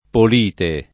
[ pol & te ]